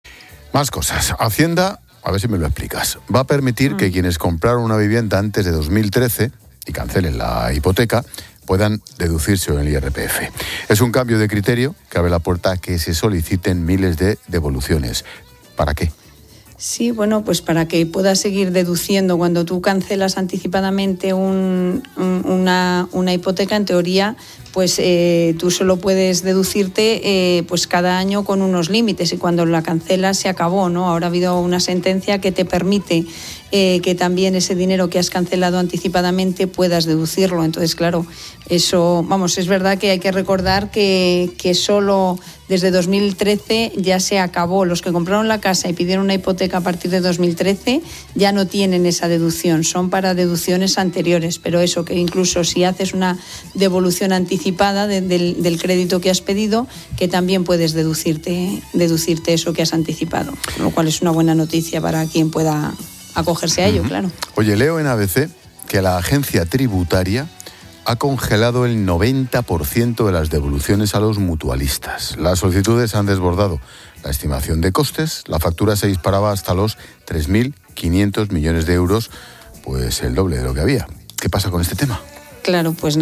Durante la conversación en 'La Linterna', también se mencionó otro asunto de actualidad fiscal: la congelación de las devoluciones a los mutualistas.